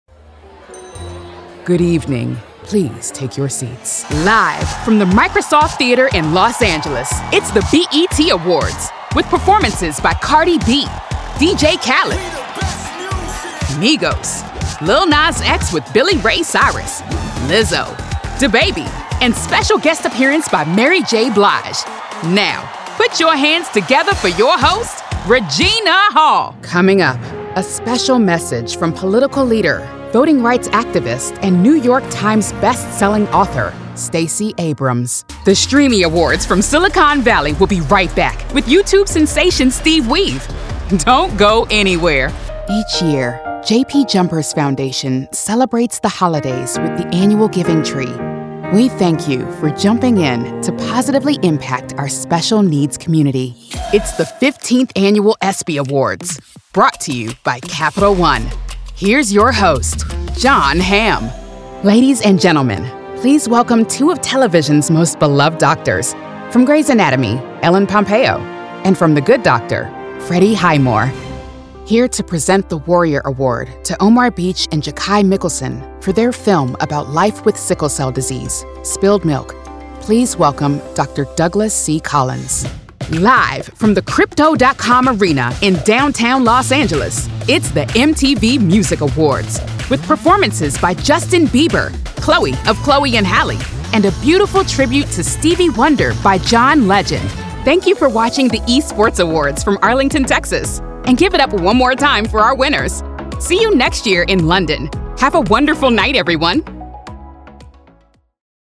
All of our contracted Talent have broadcast quality home recording studios.
Live Announce Demo Video Demos No video registered.